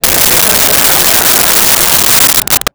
Crowd Laughing 04
Crowd Laughing 04.wav